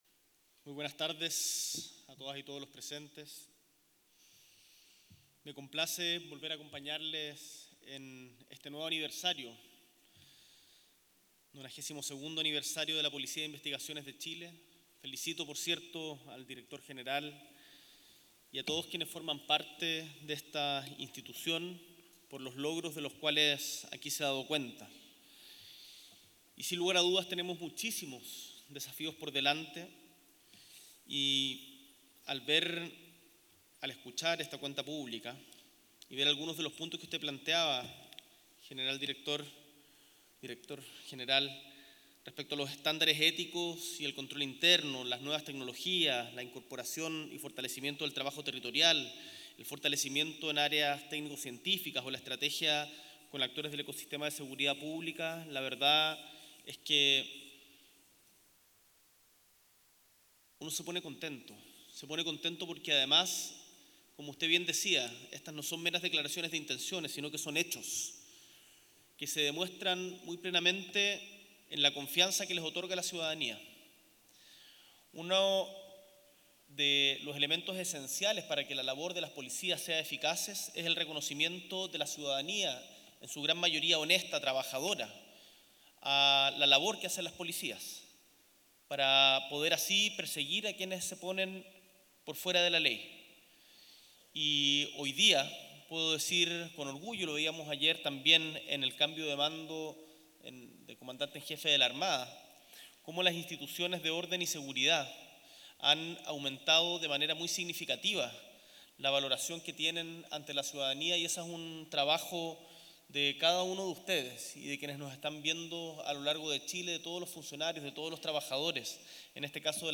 S.E. el Presidente de la República, Gabriel Boric Font, participa del aniversario 92° de la Policía de Investigaciones
En el marco de la ceremonia de aniversario, el Director General de la PDI, Eduardo Cerna, realizó la Cuenta Pública 2025 de la institución policial.